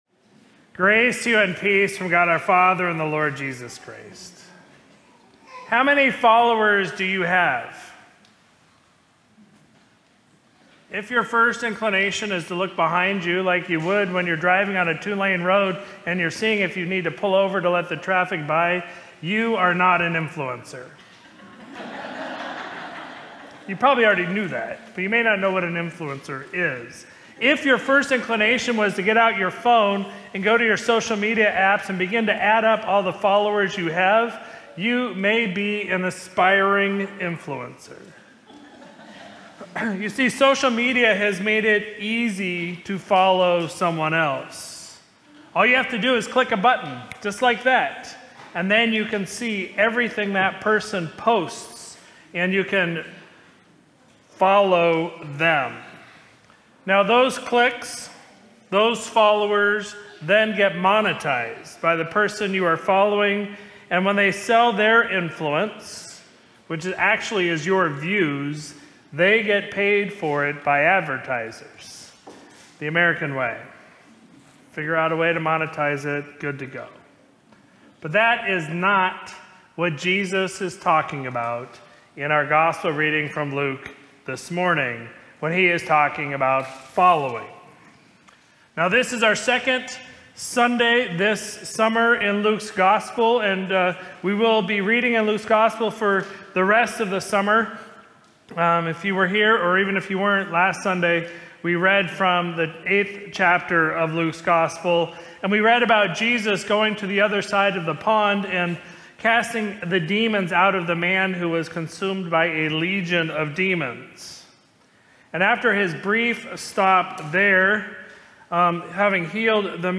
Sermon from Sunday, June 29, 2025